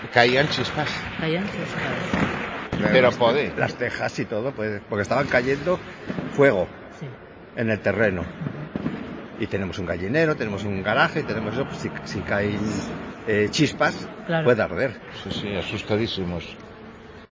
Los vecinos que han tenido que dejar sus casas están siendo atendidos en el pabellón de deportes de Ribadeo